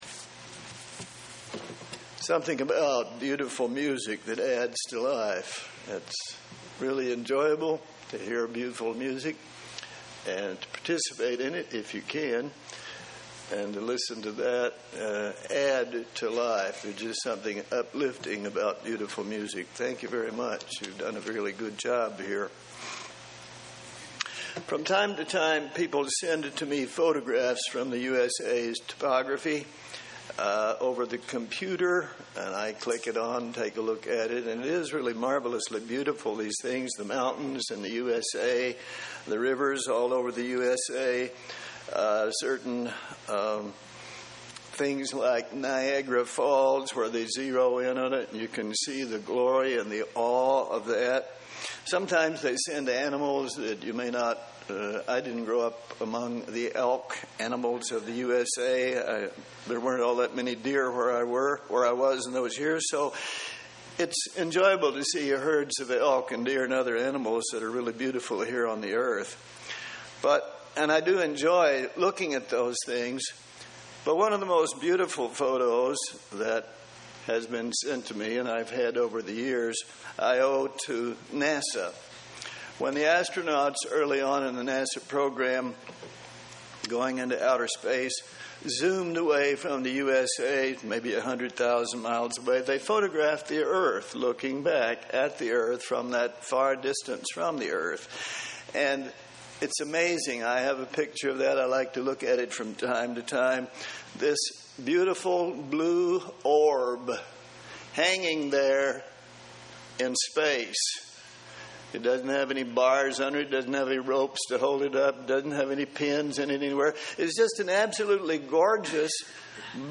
Given in Columbus, OH
UCG Sermon Studying the bible?